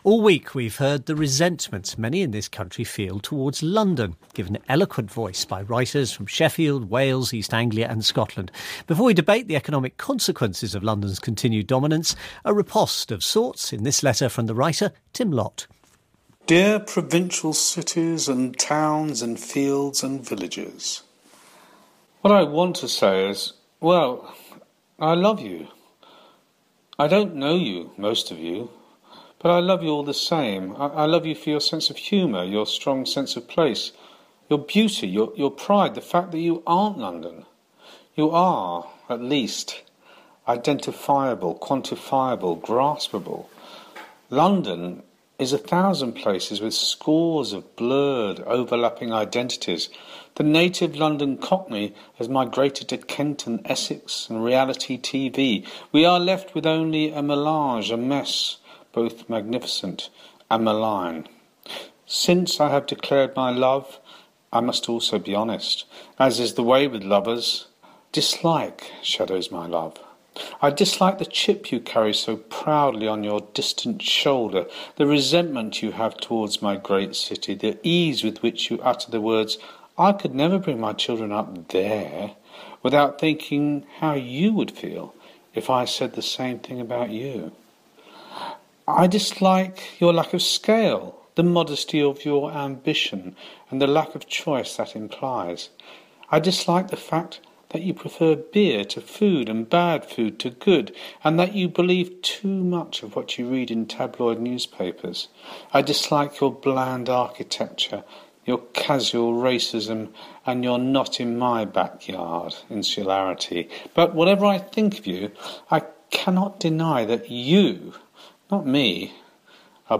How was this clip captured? broadcast on BBC Radio 4's The World at One, 29 August 2014